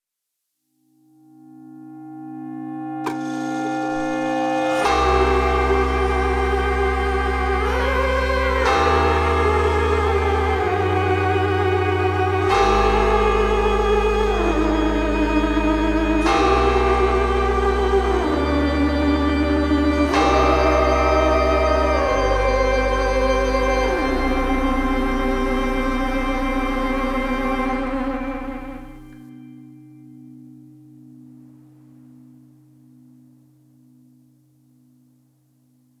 Hier drei Musikauszüge aus der aktuellen Inszenierung.